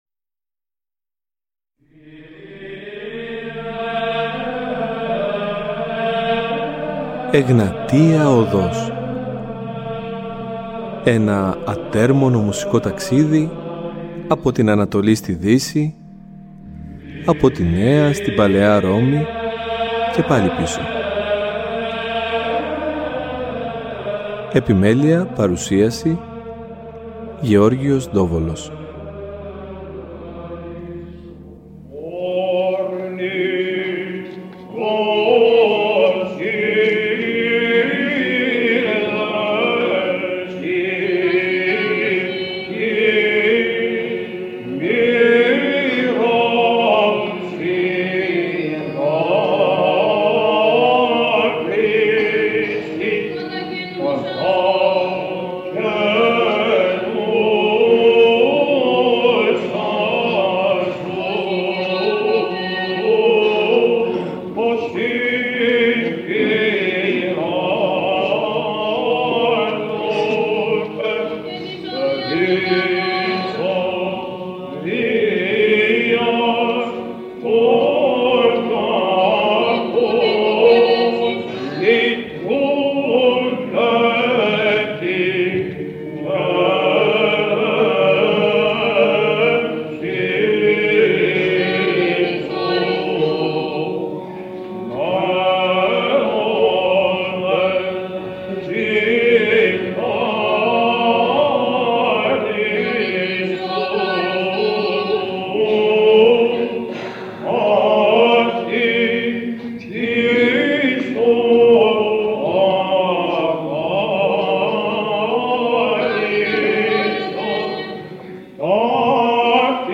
Η δεύτερη εκπομπή στα πλαίσια ενός τετράπτυχου αφιερώματος στο οποίο ακούμε διαφορετικές μελοποιητικές προσεγγίσεις αλλά και σχόλια για το γνωστό τροπάριο, προσεγγίζοντας με αυτήν την αφορμή σύνολη την βυζαντινή λογοτεχνία .